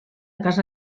keyword-spotting
speech-commands